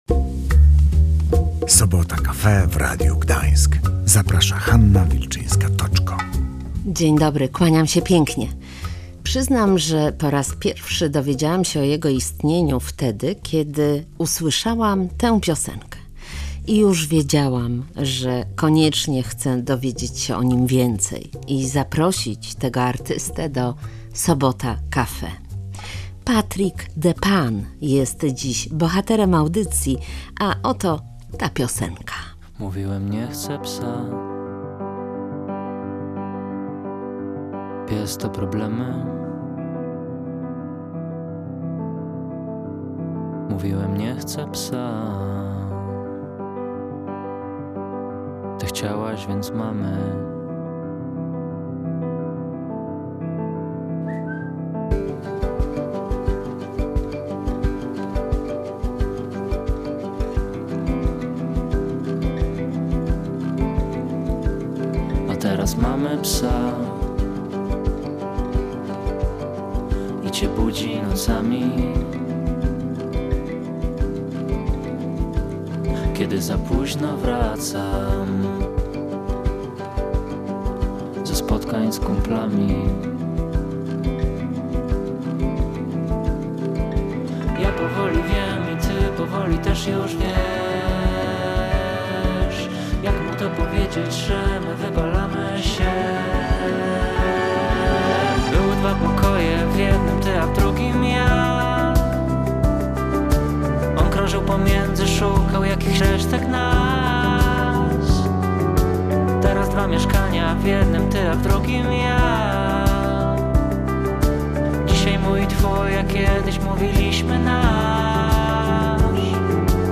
Patrick the Pan, czyli Piotr Madej opowiadał w „Sobota Cafe” o swoich piosenkach, szczęśliwym czasie podczas pandemii i autentycznej historii rozpadu związku z psem w tle.
/audio/dok3/sobotacafe_110921_net.mp3 Tagi: archiwum audycji Dawid Podsiadło muzyka premiera rozmowa wywiad